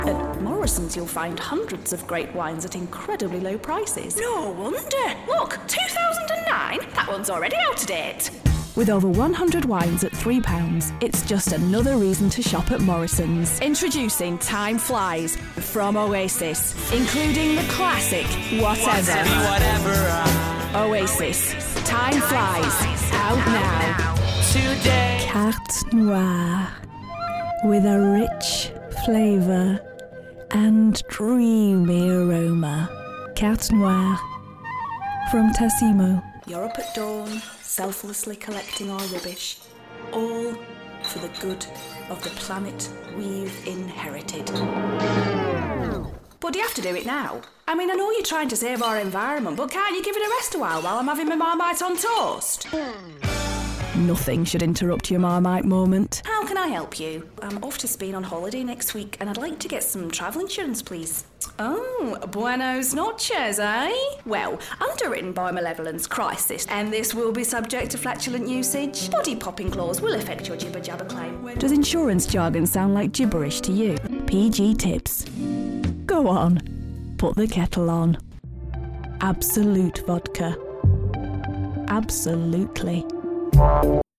Voix off
Comédienne anglaise, beaucoup d'expérience théâtrale, des publicité, des voix off, de la formation.